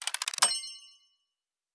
Special & Powerup (32).wav